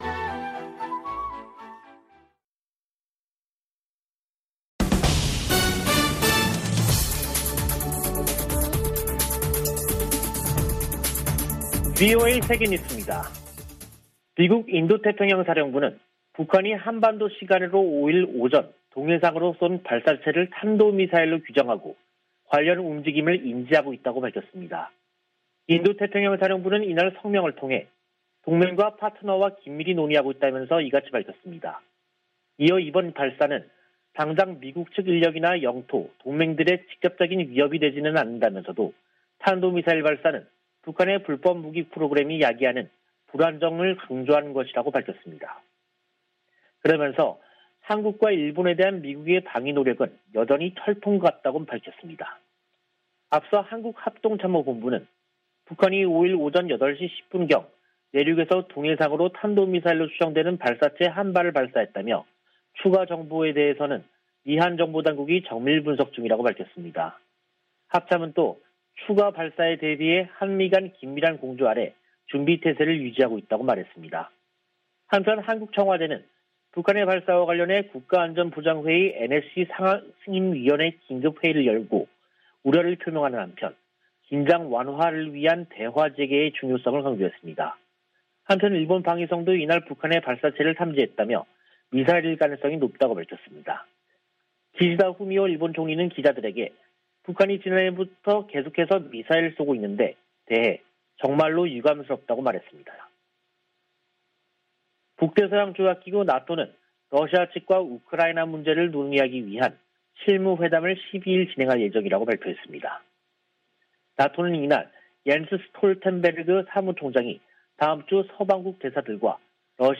VOA 한국어 간판 뉴스 프로그램 '뉴스 투데이', 2022년 1월 5일 2부 방송입니다. 북한이 동해상으로 탄도미사일로 추정되는 발사체를 쐈습니다.